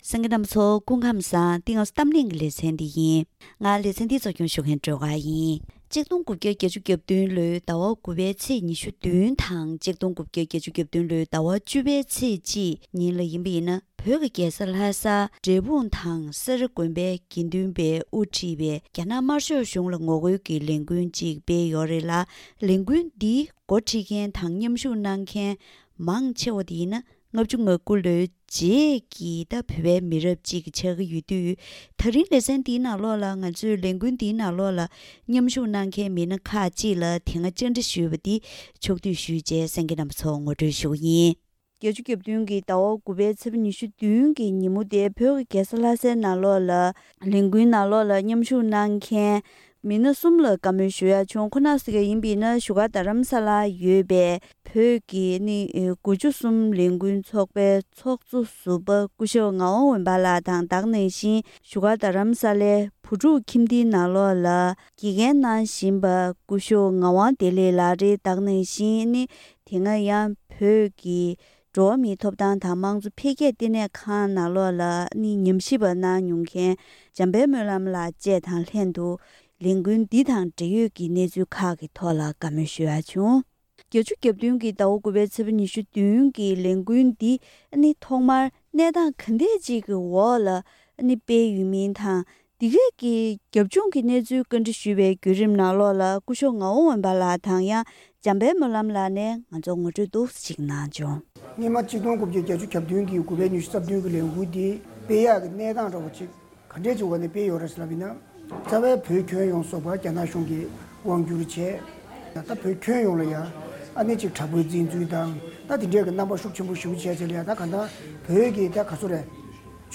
་༡༩༨༧ལོའི་ཟླ་༩ཚེས་༢༧དང་ཟླ་༡༠ཚེས་༡ཉིན་བོད་ཀྱི་རྒྱལ་ས་ལྷ་སར་འབྲས་སྤུངས་དགོན་པ་དང་སེ་ར་དགོན་པའི་དགེ་འདུན་པས་དབུ་ཁྲིད་པའི་རྒྱ་ནག་དམར་ཤོག་གཞུང་ལ་ངོ་རྒོལ་གྱི་ལས་འགུལ་ཐོན་ཡོད་པ་དང་། ལས་འགུལ་འདི་ནི་༡༩༥༩ལོའི་རྗེས་ཀྱི་མི་རབས་གསར་པས་སྤེལ་བའི་ལས་འགུལ་ཞིག་ཆགས་ཀྱི་ཡོད་པས། སྐབས་དེ་དུས་རྒྱ་ནག་གཞུང་གིས་མཚོན་ཆ་བེད་སྤྱོད་བཏང་སྟེ་བོད་མིར་དྲག་གནོན་བྱས་པའི་གནས་ཚུལ་ཁག་ལས་འགུལ་ནང་དངོས་སུ་མཉམ་ཞུགས་གནང་མཁན་གྱི་མི་སྣ་དང་ལྷན་དུ་བཀའ་མོལ་ཞུས་པ་ཞིག་གསན་རོགས་གནང་།